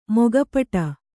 ♪ moga paṭa